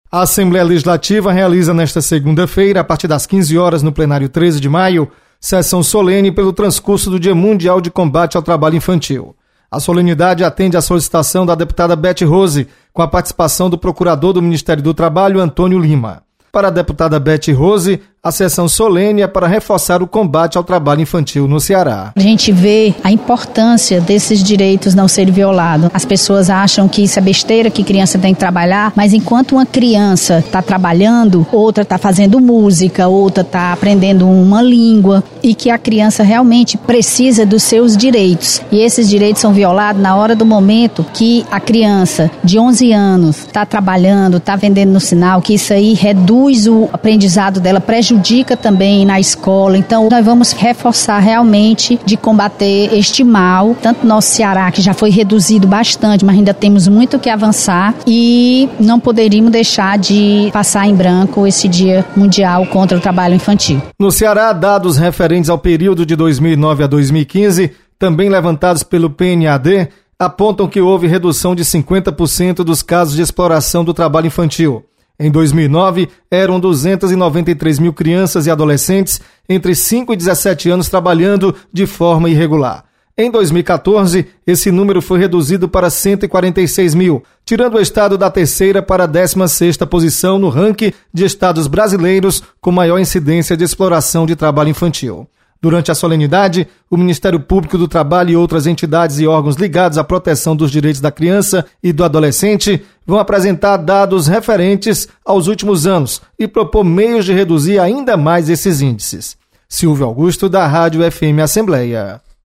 Deputada Bethrose comanda sessão solene em homenagem ao Dia Mundial de Combate ao Trabalho Infantil. Repórter